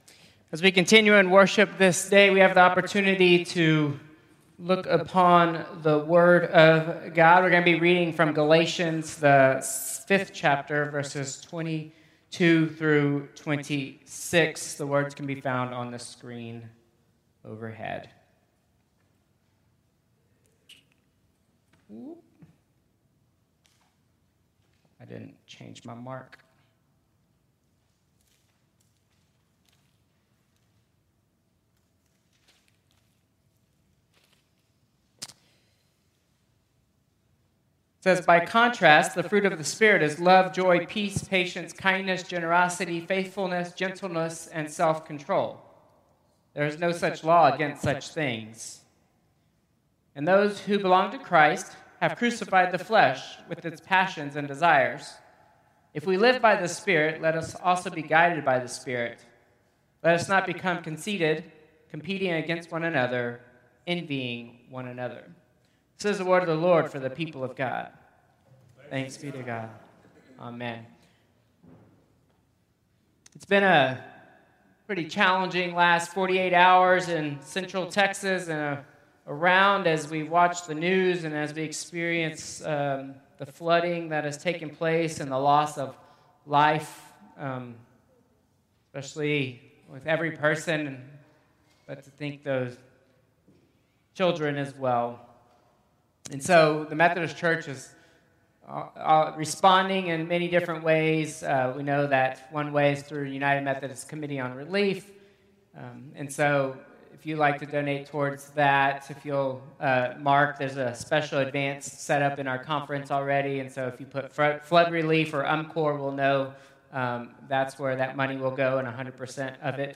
Traditional Service 7/6/2025